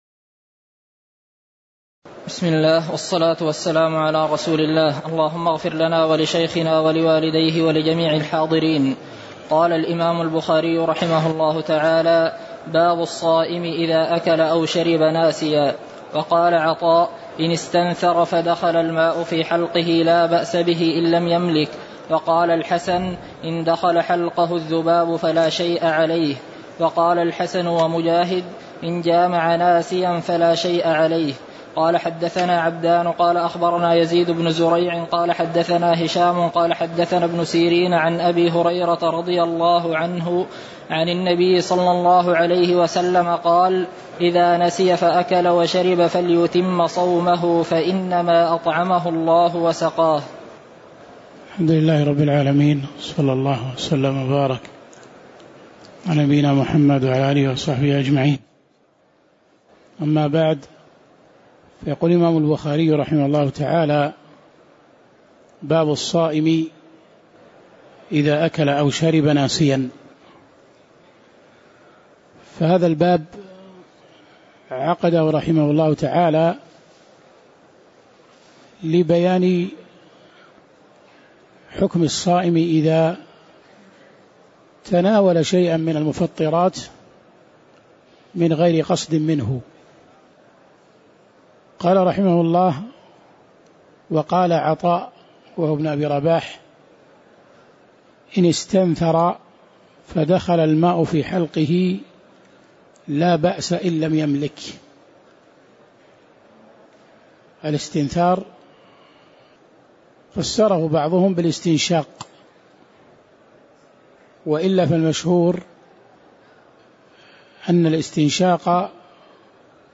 تاريخ النشر ٨ رمضان ١٤٣٨ هـ المكان: المسجد النبوي الشيخ